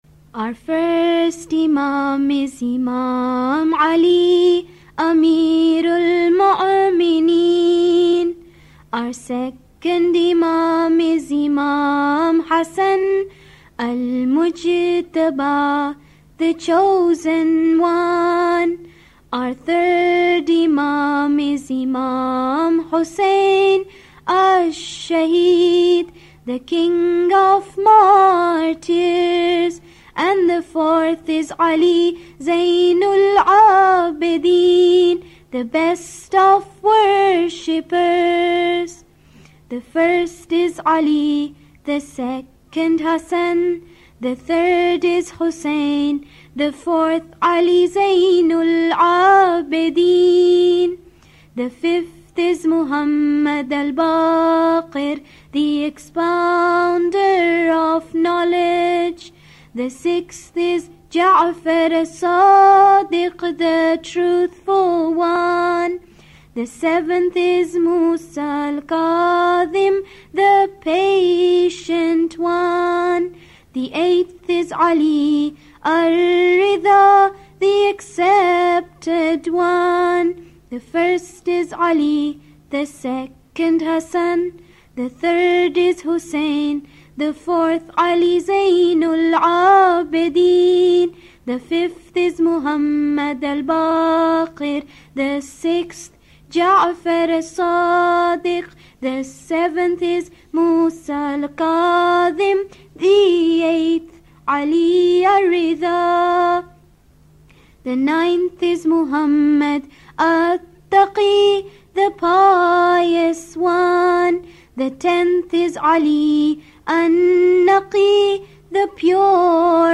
Part of a series of Muyslim rhymes for children.